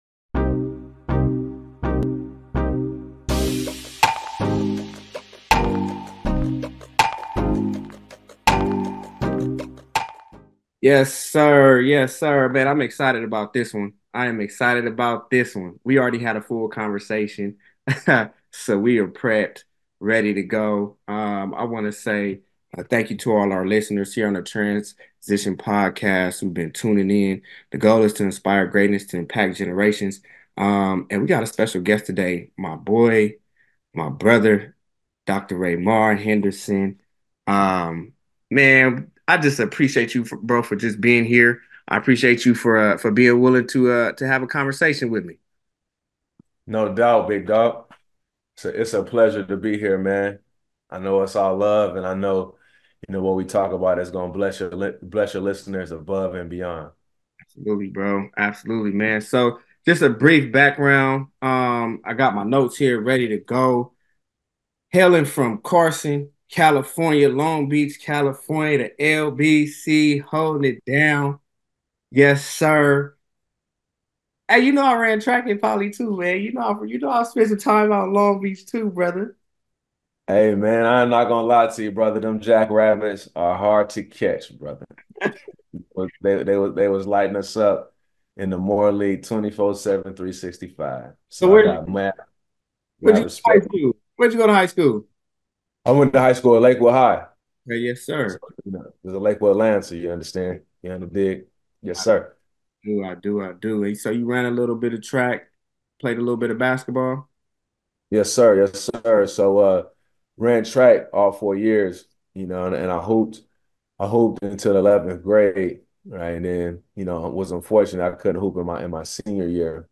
Mentorship: Both speakers stress the value of mentorship in athletes' lives, advocating for relati